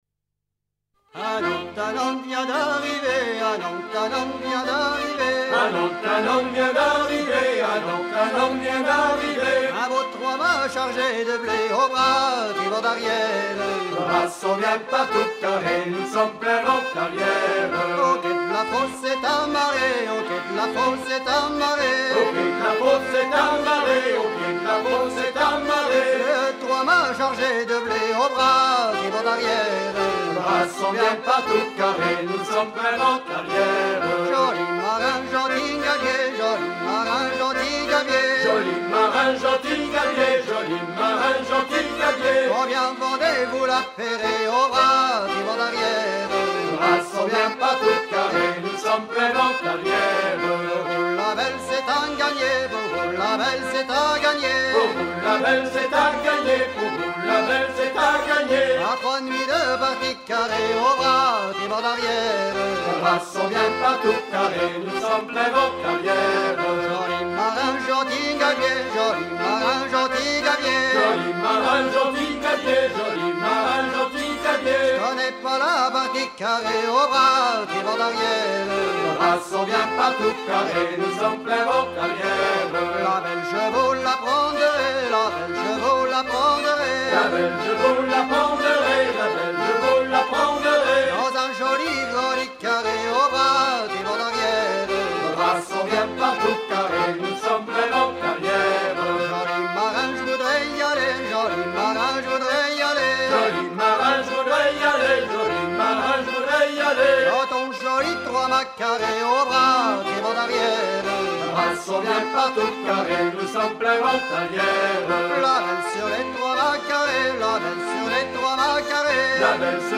à virer au cabestan
Genre laisse
Pièce musicale éditée